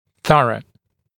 [‘θʌrə][‘сарэ]исчерпывающий, полный, всесторонний, тщательный